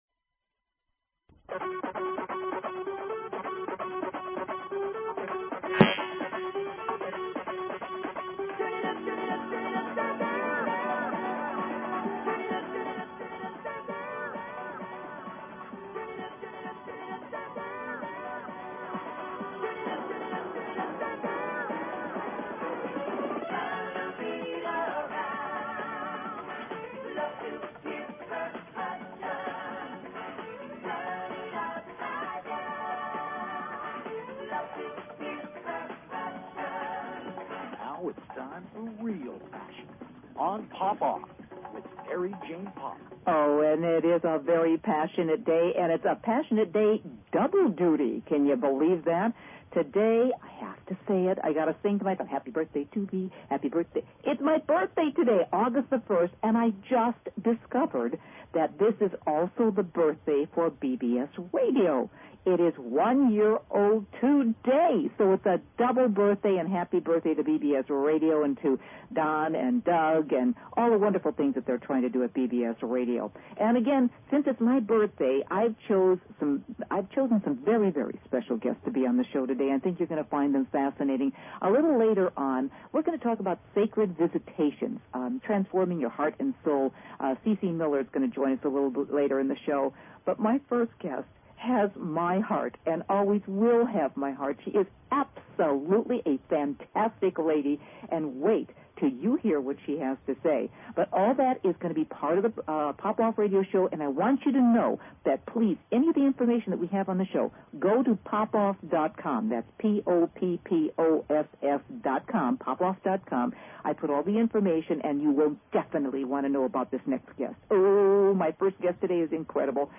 Talk Show Episode, Audio Podcast, Poppoff and Courtesy of BBS Radio on , show guests , about , categorized as
A fast-paced two hour Magazine-style Show dedicated to keeping you on the cutting edge of today's hot button issues.